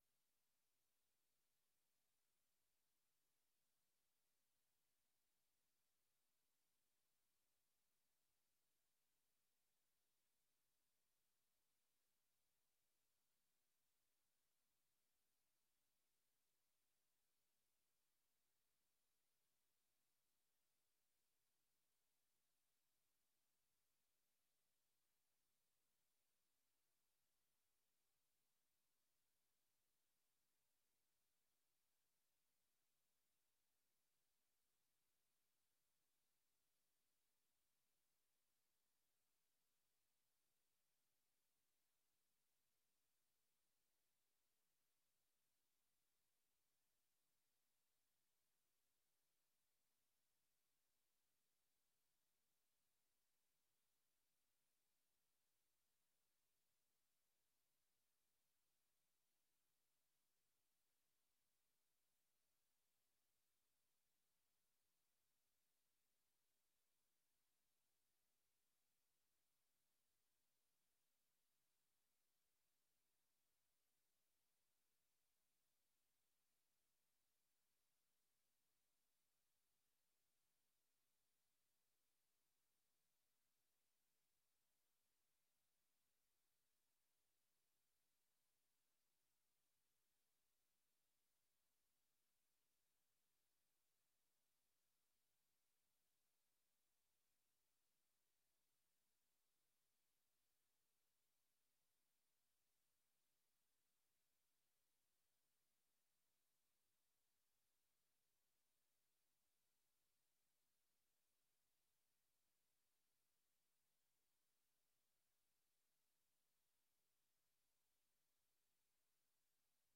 Beeldvormende vergadering(en) 02 december 2025 20:00:00, Gemeente West Betuwe
Locatie beeldvormende vergadering 1 raadszaal